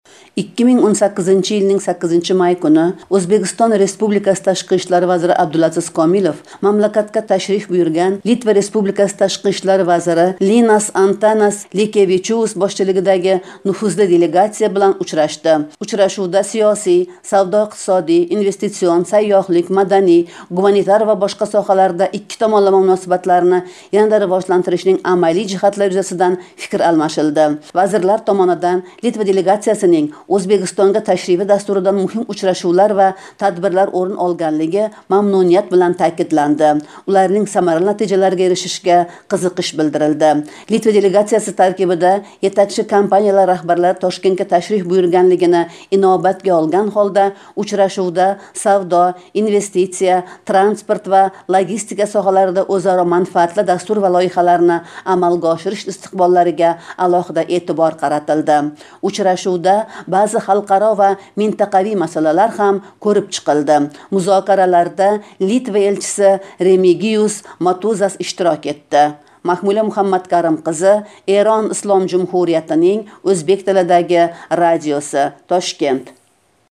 Янгиликлар